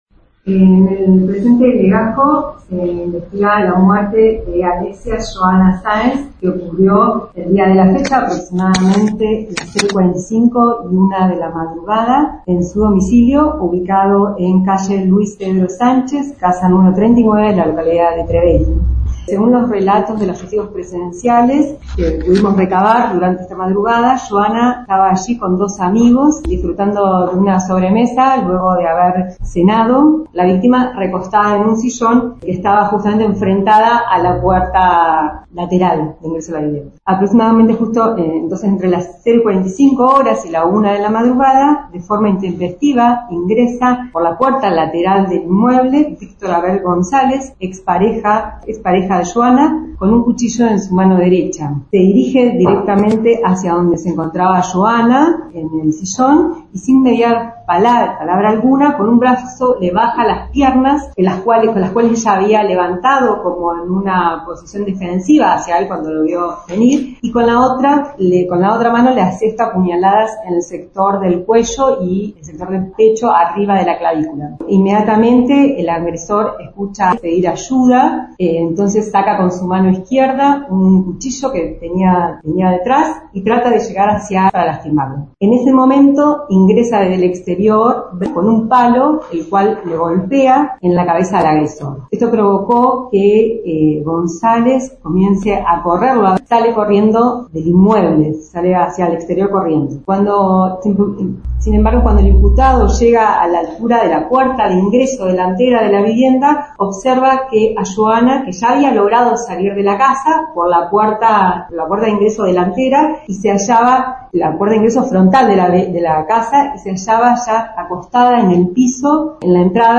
Audiencia